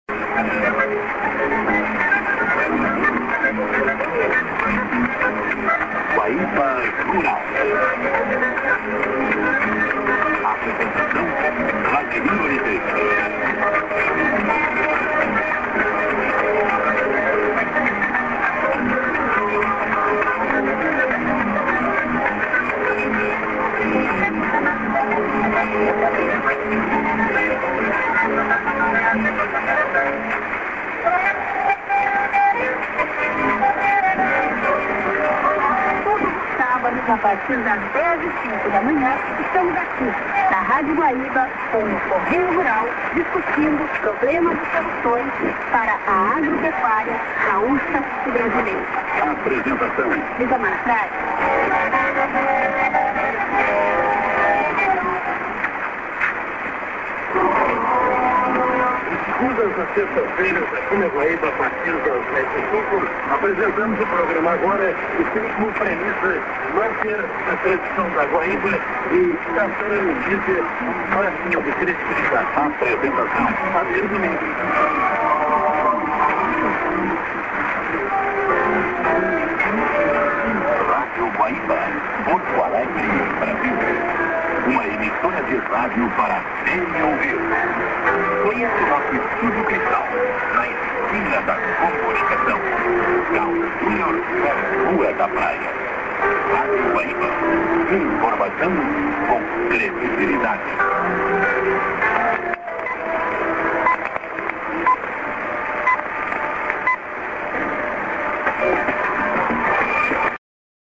->00'45"ID(women)->01'20":ID(man)->TS->　今年最初の録音局です。